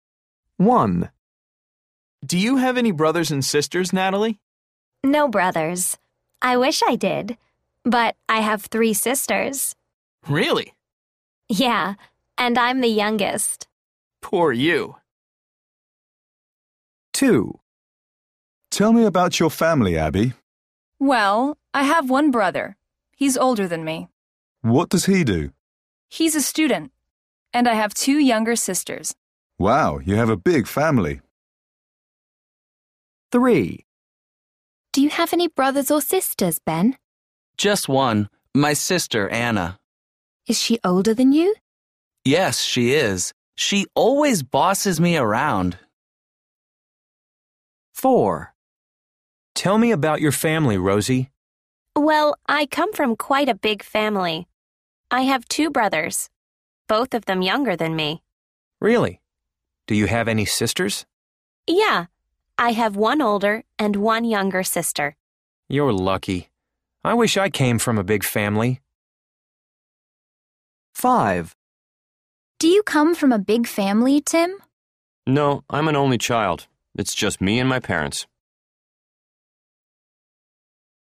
A. People are talking about their families.